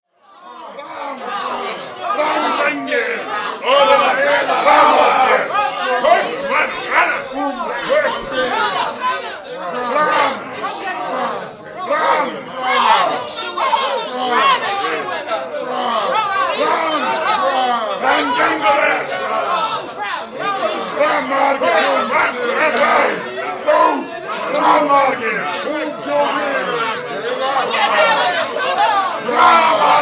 Angry Saxon crowd
CrowdAngrySaxons.mp3